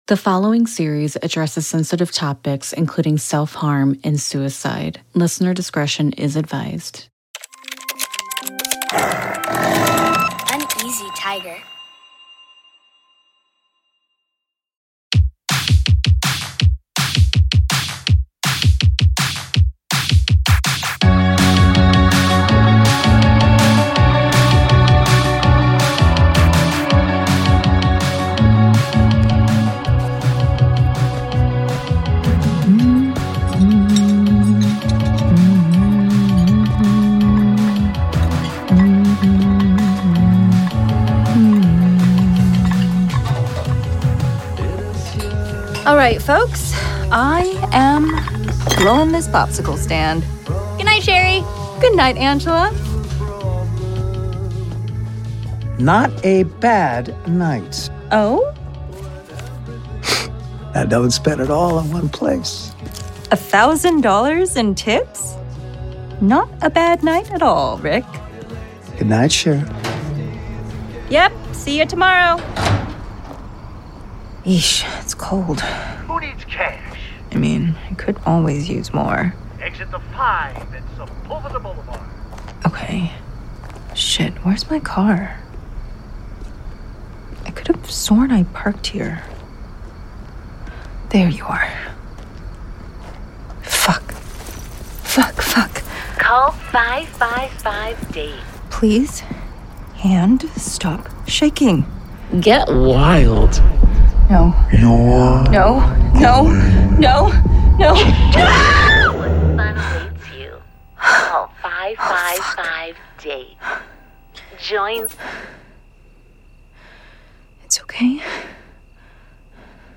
*Featured on Apple Podcasts Top Charts for Fiction and Drama*